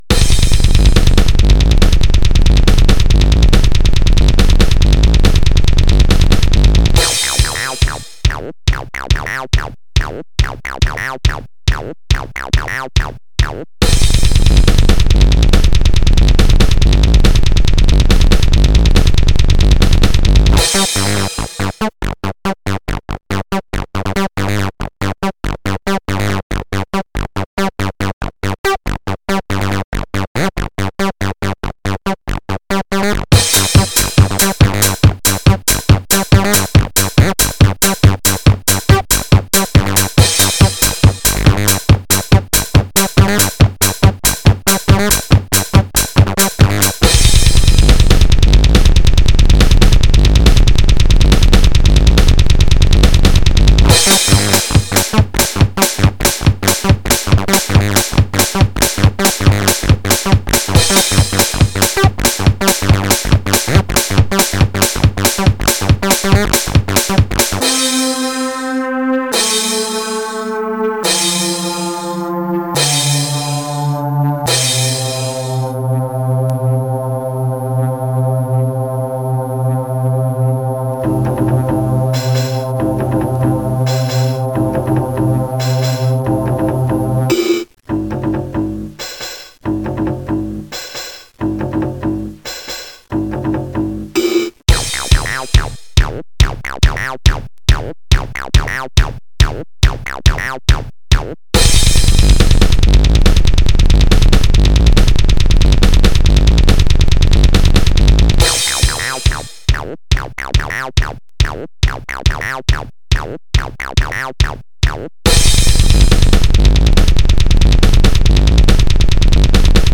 Genre: synthwave, electronic.